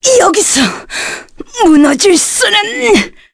Demia-Vox_Dead_kr.wav